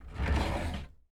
ES_Drawer Open Hotel 5 - SFX Producer.wav